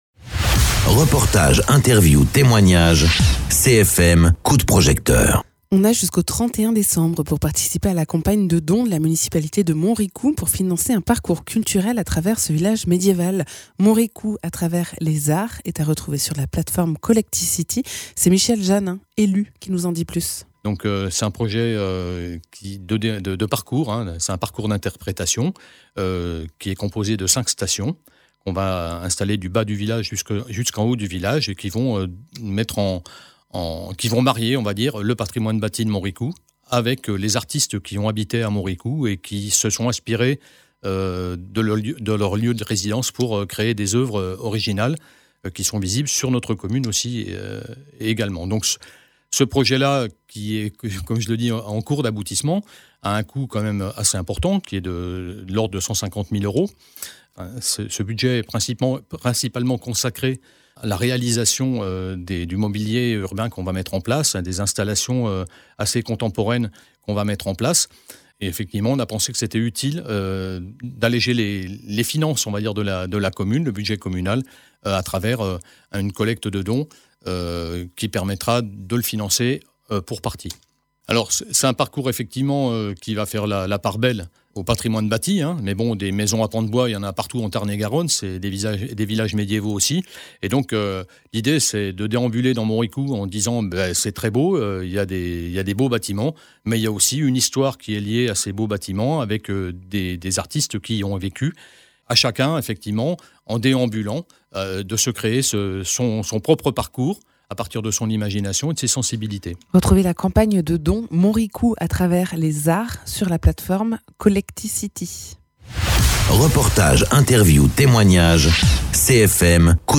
Interviews
Invité(s) : Michel Jannin, élu à Montricoux